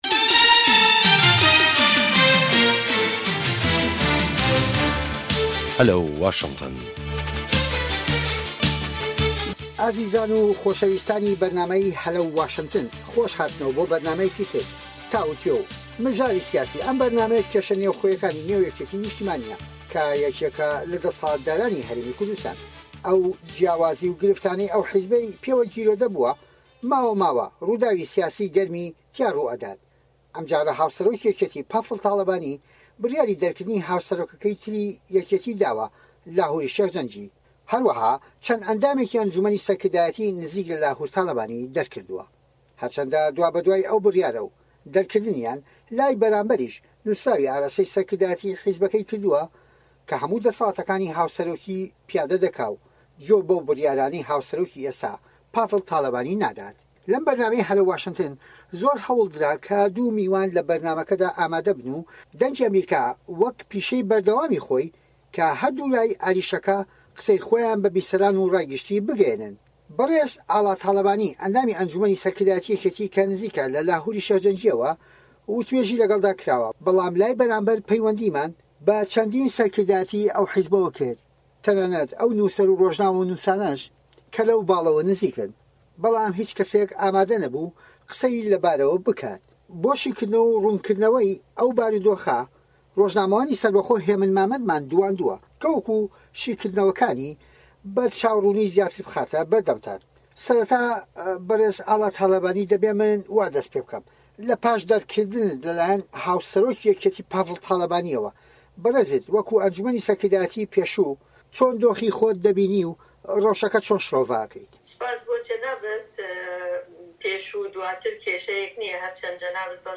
لەم بەرنامەیەی هەلەو واشنتندا میوانداری هەریەک لە بەڕێزان، ئاڵا تاڵەبانی، ئەندامی ئەنجومەنی سەرکردایەتی یەکێتی